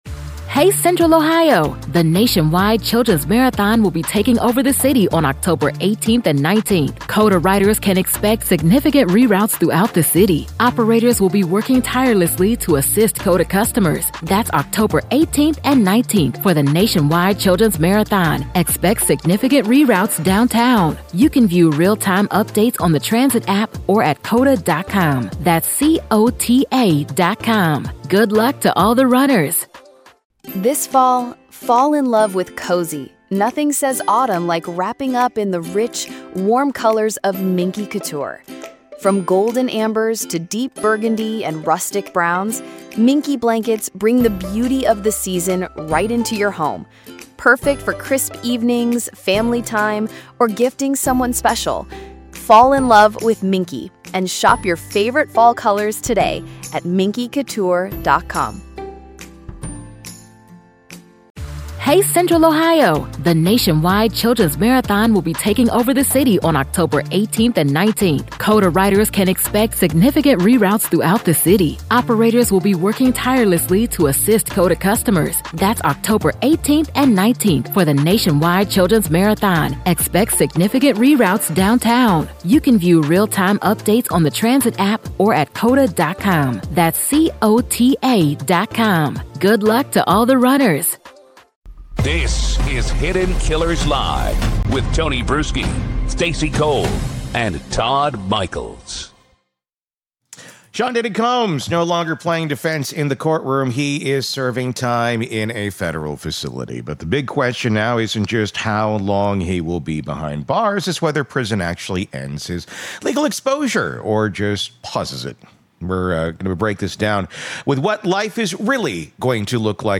This interview lays bare the next chapter—and how the federal government may still be flipping pages.